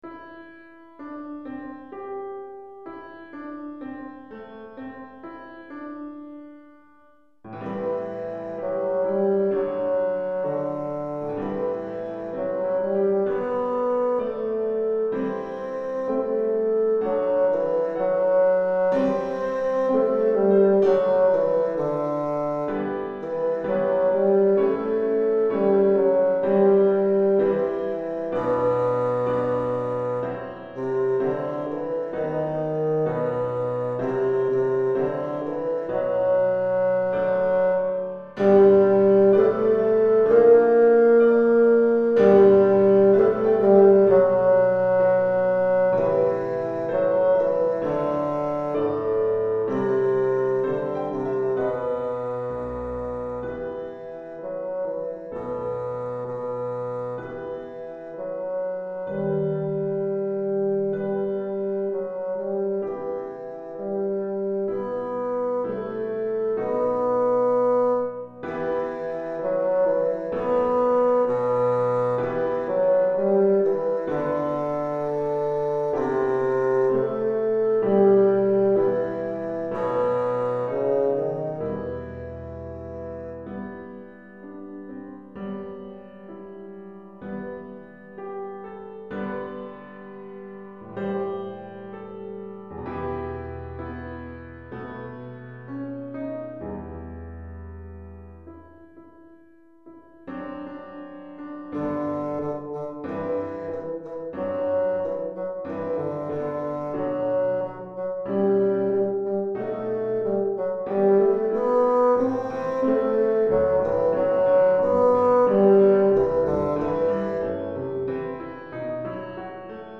pour basson et piano
Basson et piano